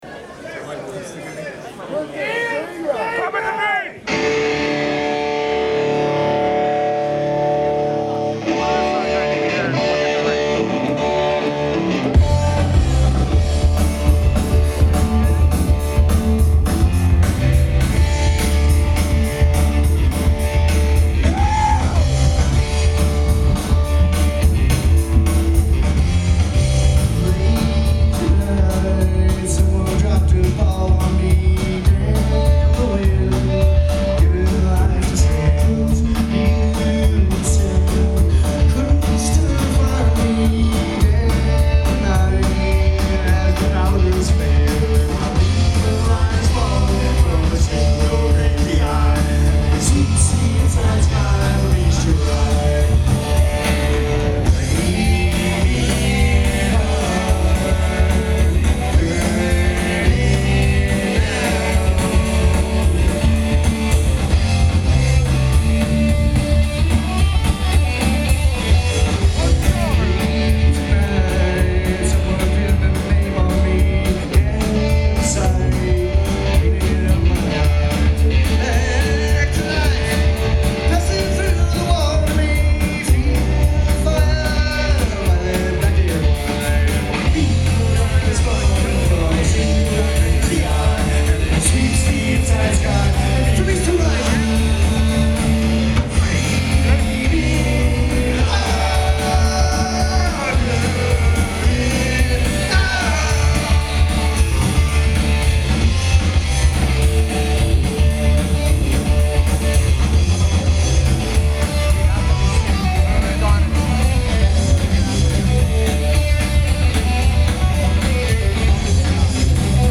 Mic: Sony ECM MS907 (90-degree setting)
Mic config: handheld, chest level, pointed at center stage
Location: FOB, floor middle, 25' back from stage
Source:  Sony MZ-R37 MD Recorder